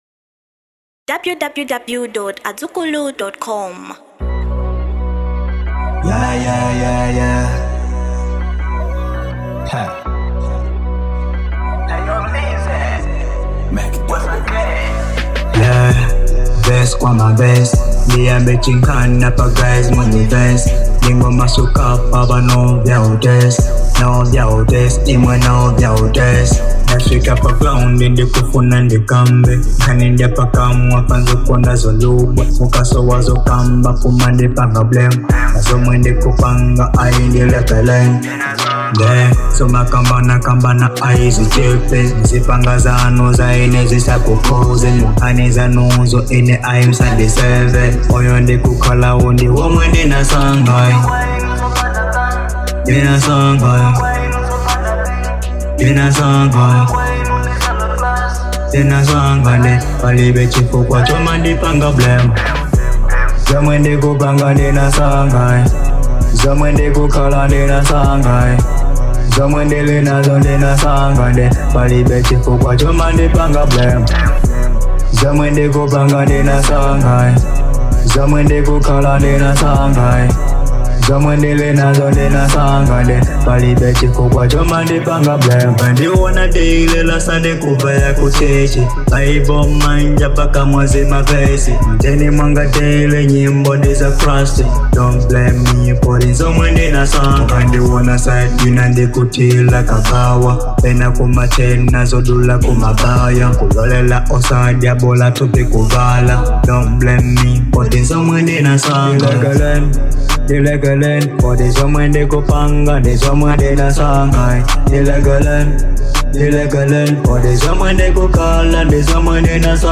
Genre HIP HOP